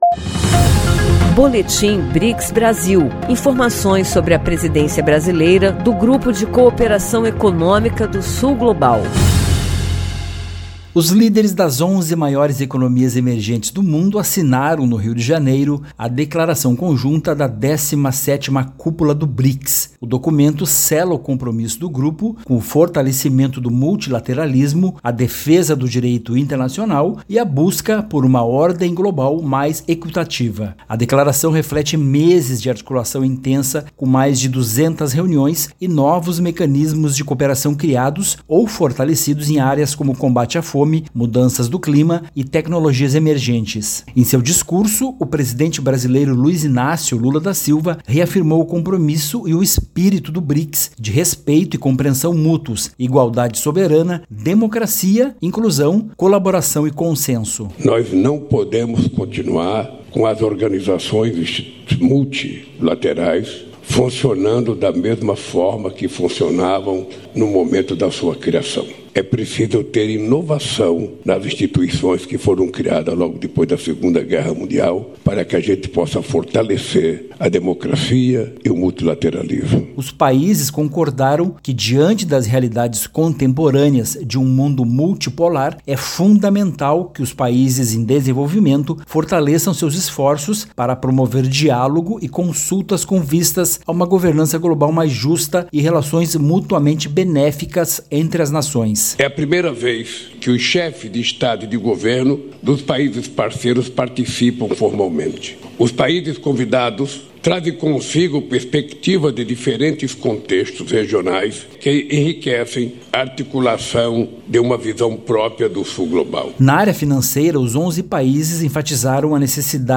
No BRICS, o presidente brasileiro critica o negacionismo climático e defende saúde global, transição energética e financiamento para países em desenvolvimento. Ouça a reportagem e saiba mais.